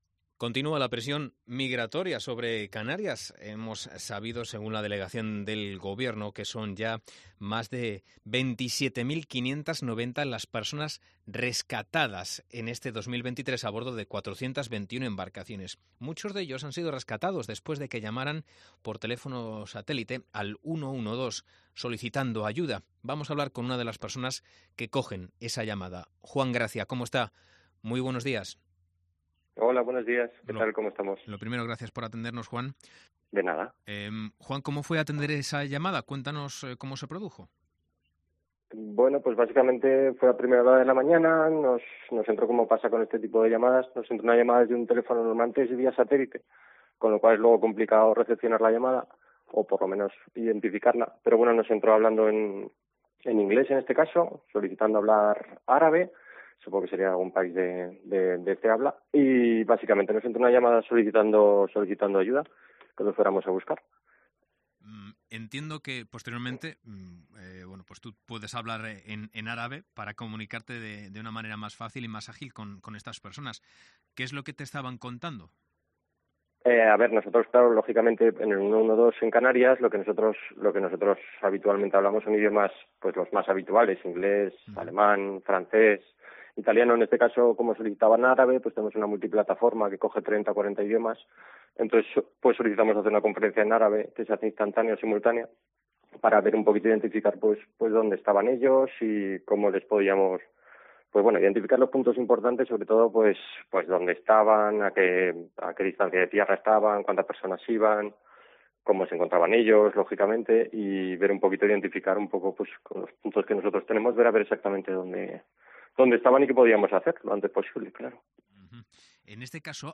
Esta mañana hemos hablado con uno de los operadores que se encargan de dar respuesta a estas personas.